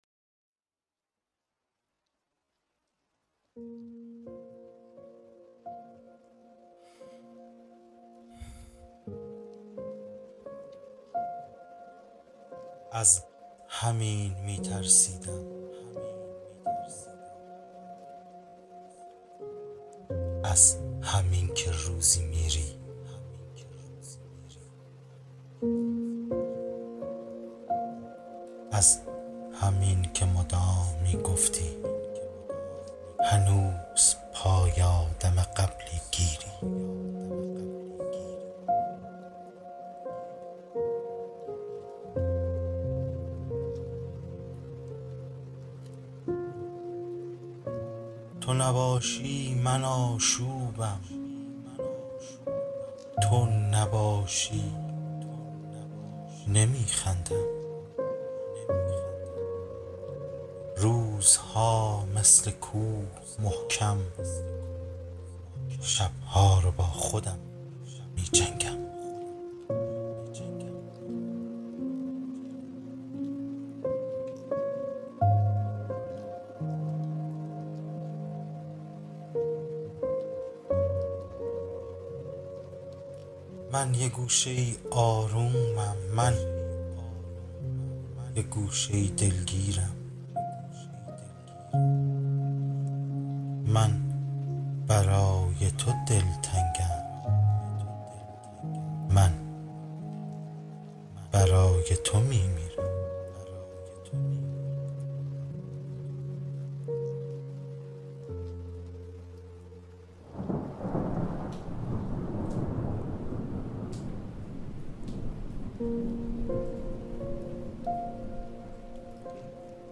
تو نباشی ... (پخش آنلاین دکلمه)